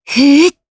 Xerah-Vox_Casting2_jp.wav